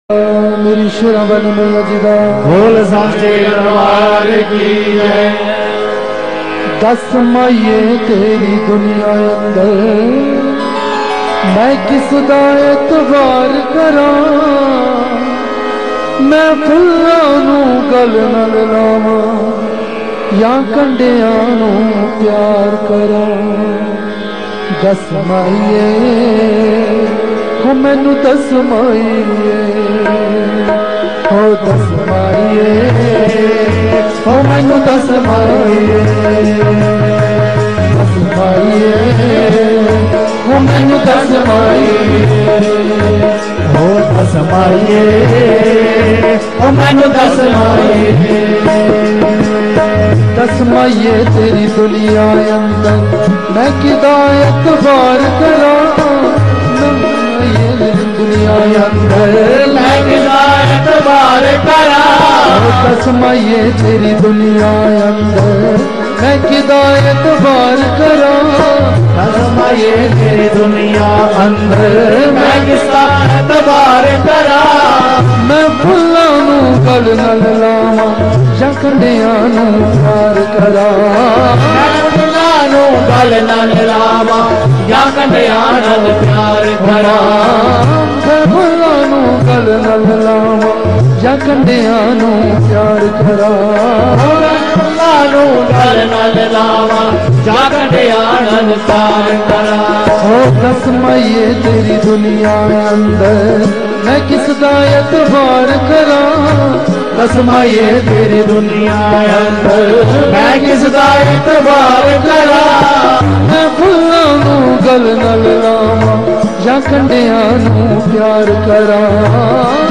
MP3 bhajans from Mata Vaishno Devi attka aarti. Explore bhajans from morning and evening aarti from Garbhjun Adhkuwari and Bhawan.